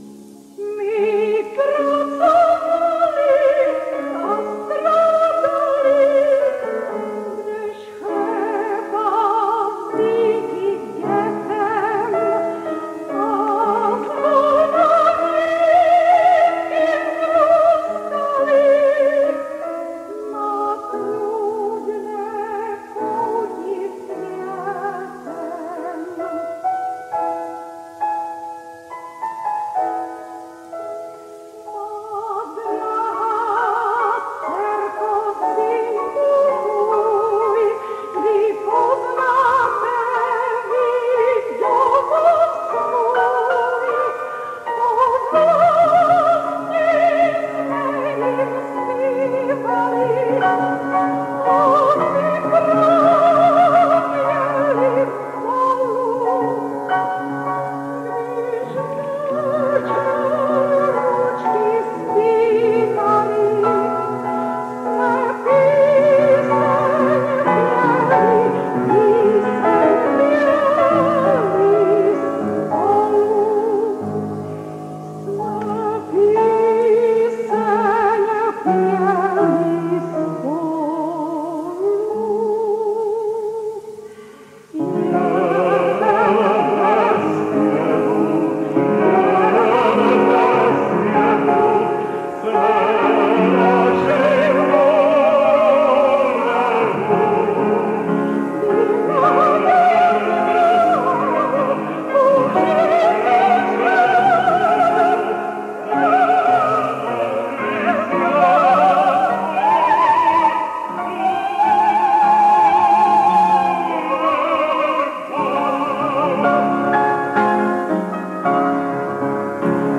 2. sloka známé árie z opery Jakobín – ze záznamu Jarního koncertu v Židlochovicích v r. 1993 (z archívu pěveckého sboru Skřivánek)